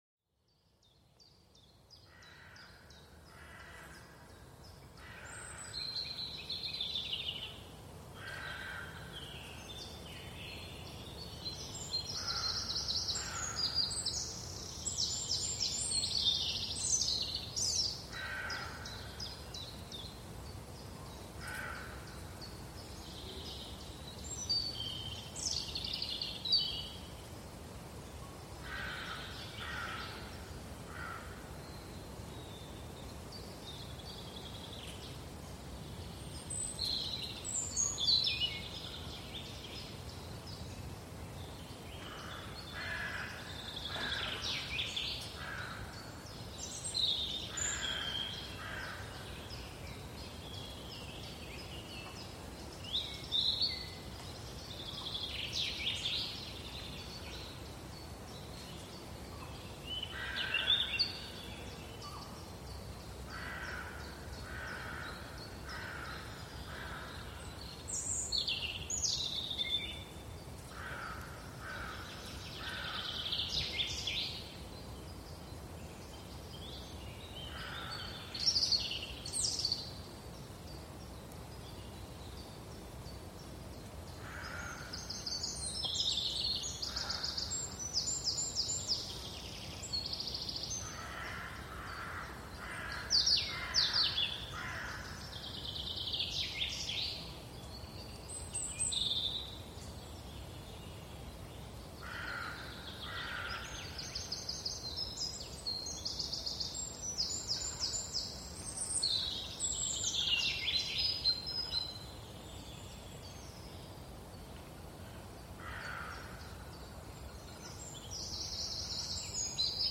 Cantos de Pájaros del Bosque: Calma y Serenidad
Sumérgete en el bosque con los relajantes cantos de los pájaros, perfectos para calmar la mente. Cada trino y gorjeo te transporta a un refugio natural de paz.
Cada episodio captura los sonidos relajantes de diferentes entornos naturales.